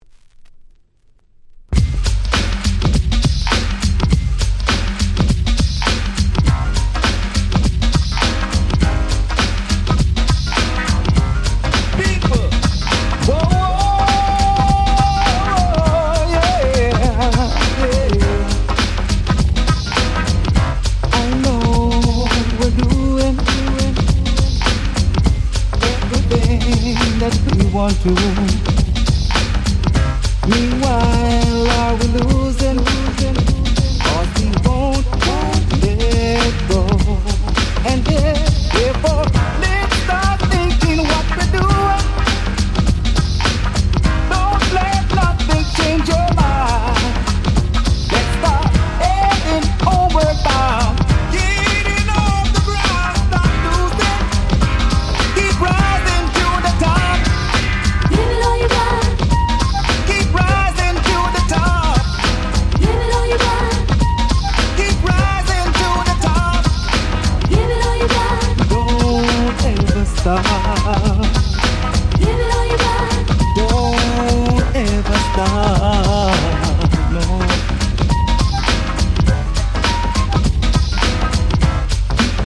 92' Very Nice Cover R&B / Reggae !!